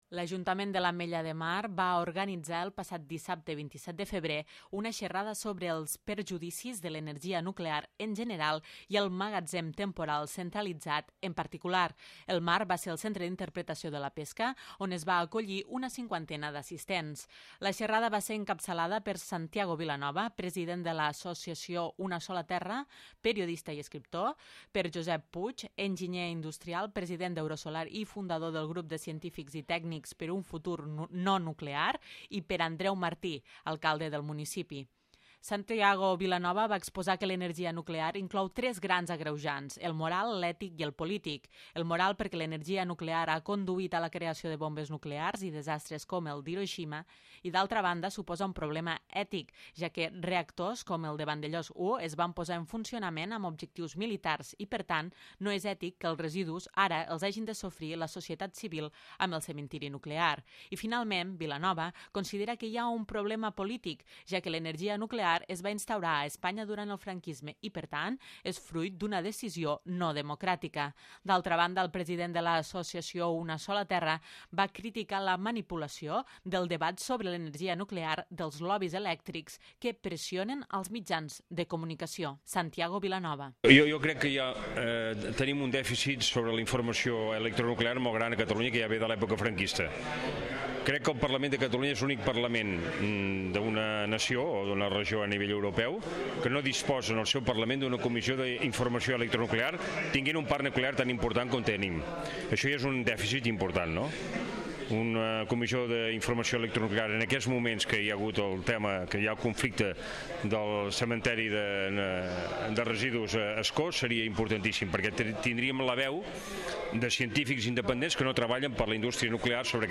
El consistori va organitzar el passat dissabte, 27 de febrer, una xerrada sobre els perjudicis de l'energia nuclear en general i el Magatzem Temporal Centralitzat (MTC) en particular. El marc va ser el Centre d'Interpretació de la Pesca on es va acollir una cinquantena d'assistents.